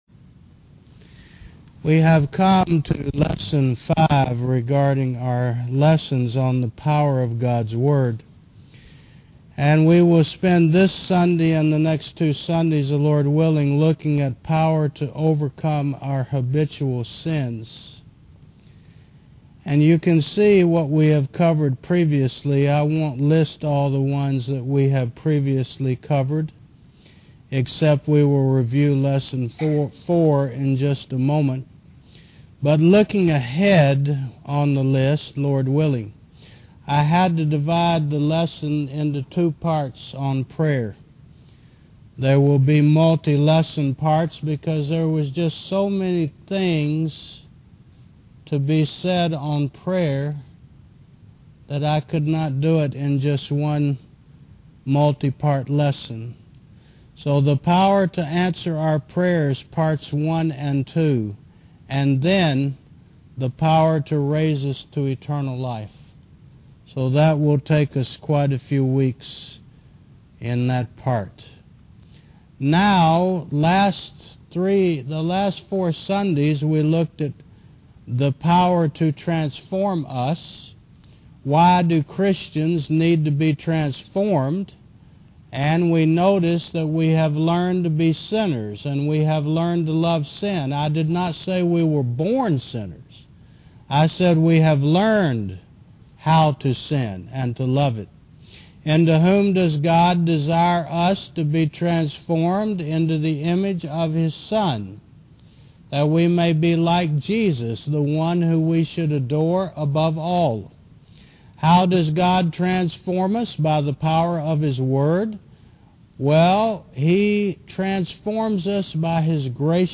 Service Type: Sun. 11 AM